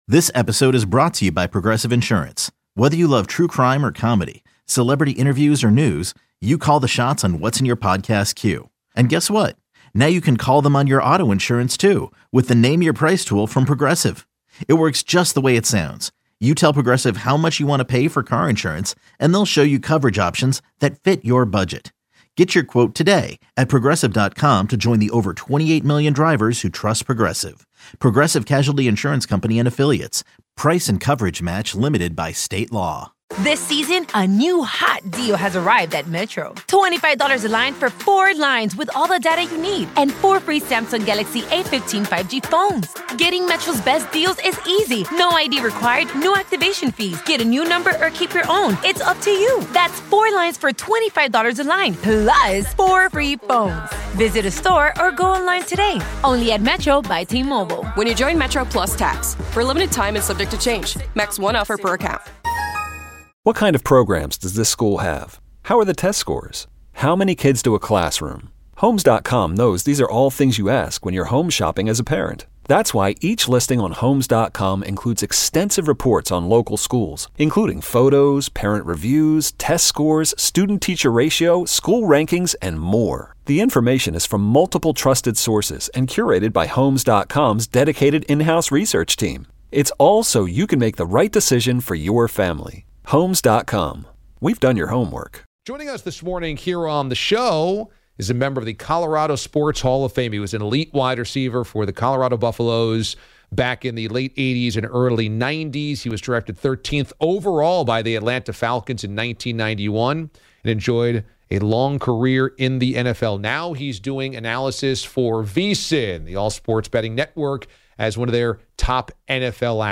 The DA Show Interviews